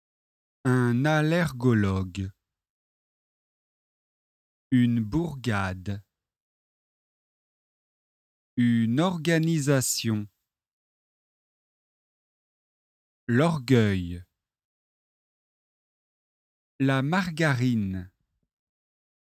🎧 Exercice 5 : écoutez et répétez.
[ r ] + [ g ]